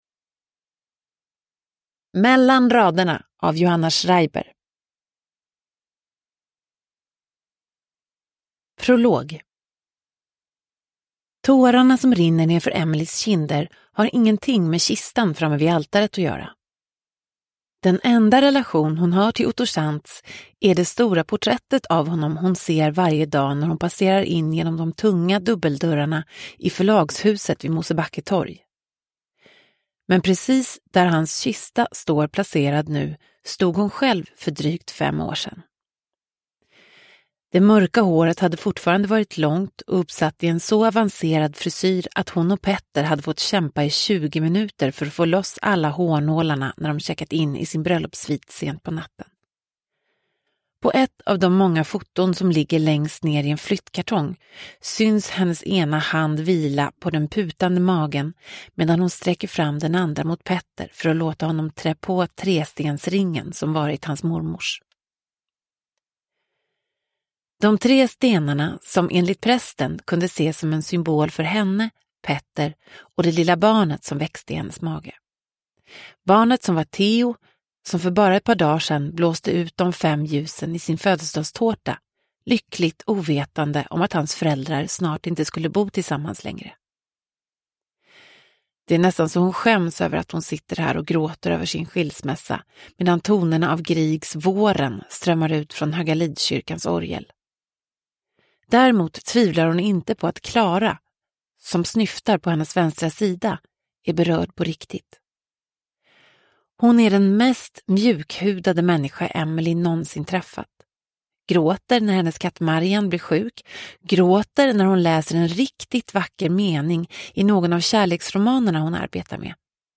Mellan raderna – Ljudbok – Laddas ner
Uppläsare: Emma Molin